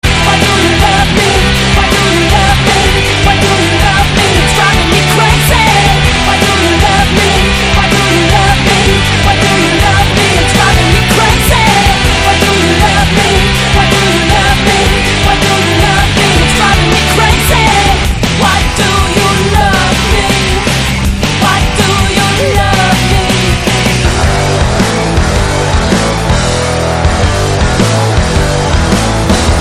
Назад в Rock